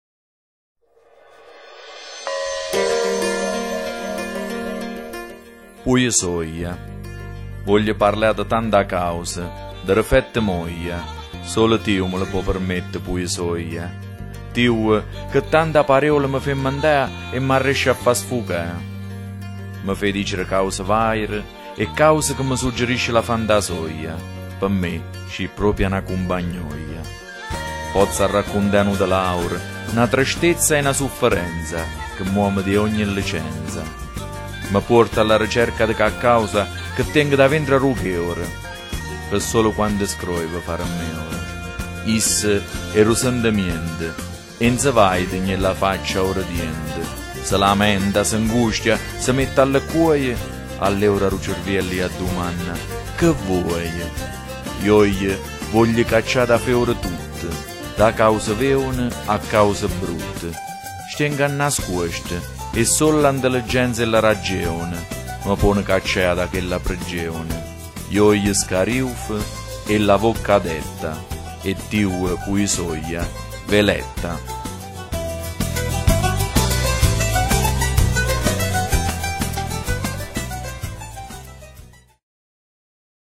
Dialetto
Poesia